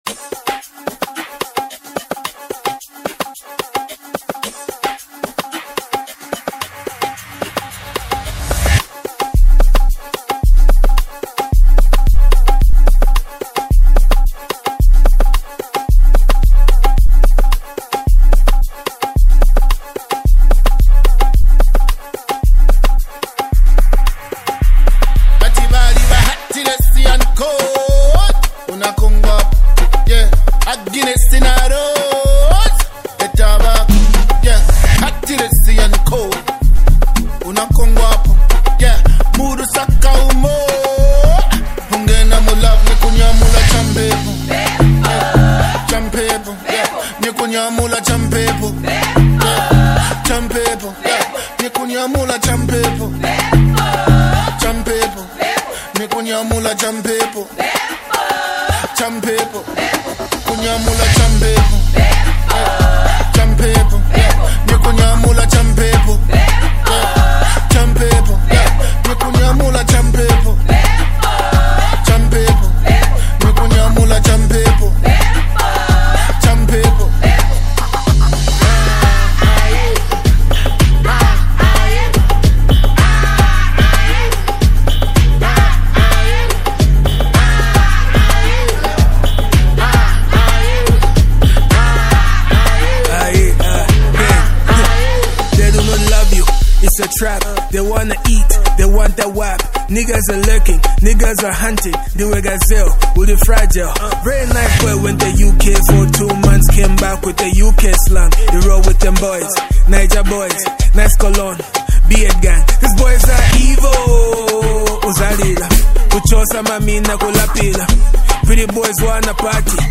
features lively beats and catchy lyrics